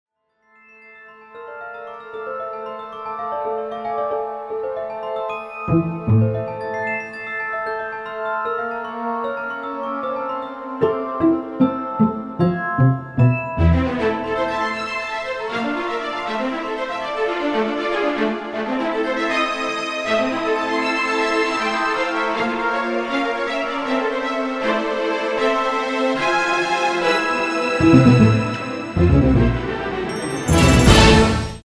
Звуковые эффекты магии, трансформации и перевоплощения идеально подойдут для монтажа видео, создания игр, подкастов и других творческих проектов.
Звук продолжительного превращения персонажа